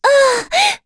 Dosarta-Vox_Damage_02.wav